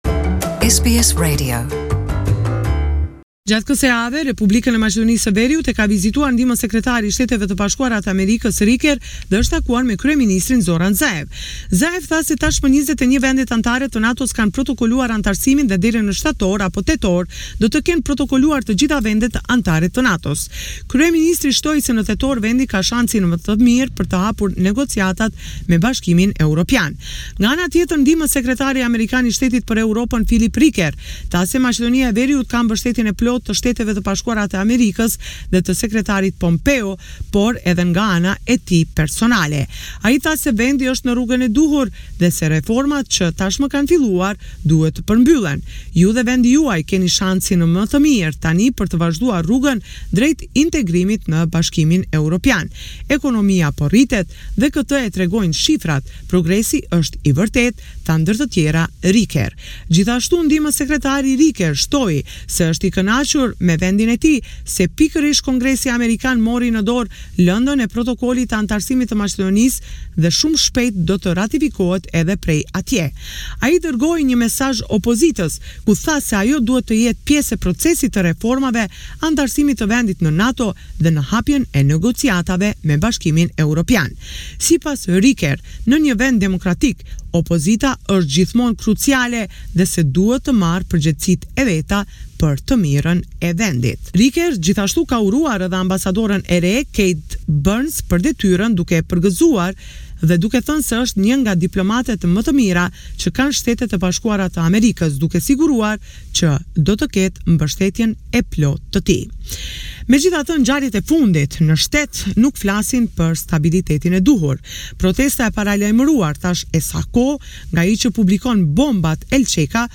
This is a report summarising the latest developments in news and current affairs in North Macedonia